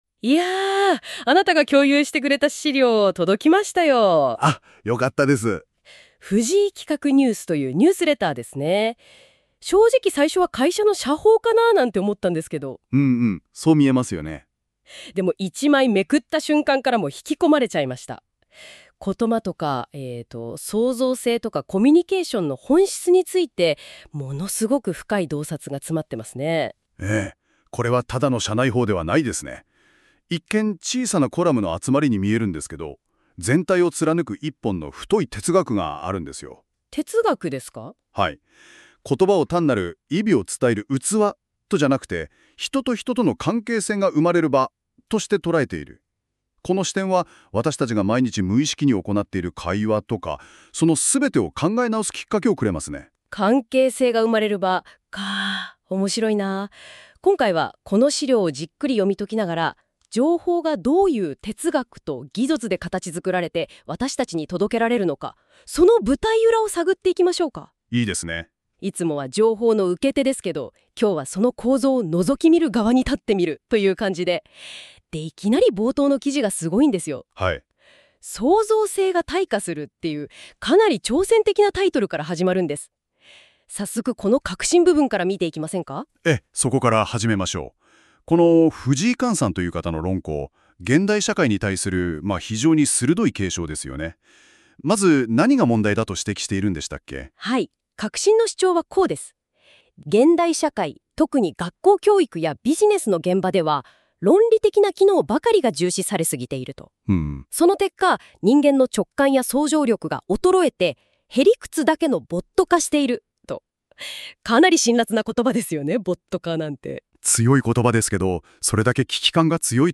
紙ブログ第60号の音声解説 なお、今回から実験的にAIによる社報『紙ブログ』紙面内容の音声解説アップしました。男女二人の対話形式で紹介しています。一度ご視聴いただければ紙面の内容が簡単にわかります。